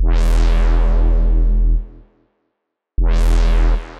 On The Move (Bass Synth) 120 BPM.wav